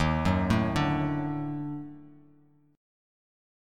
EbmM7b5 chord